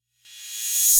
Crashes & Cymbals
808CymbRev.wav